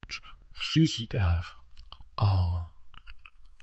multilingual text-to-speech voice-cloning